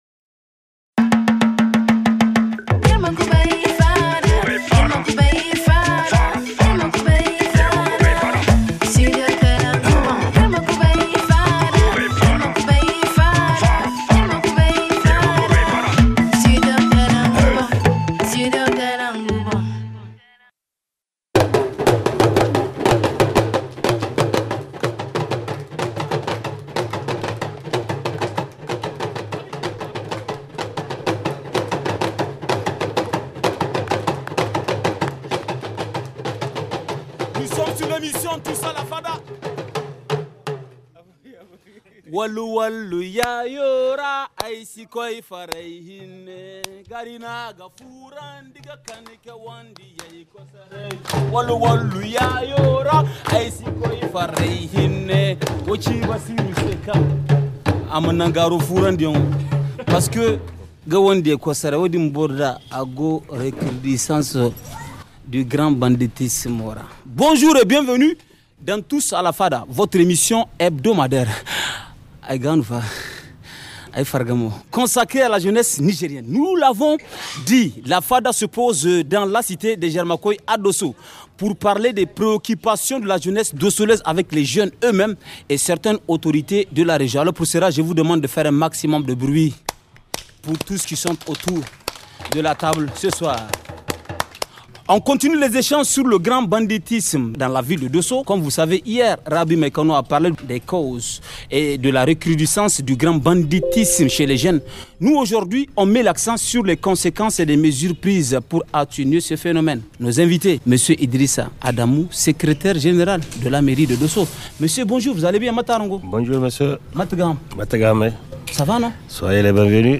Le thème du débat de ce soir est : les conséquences de la recrudescence du grand banditisme et les mesures pour atténuer le fléau.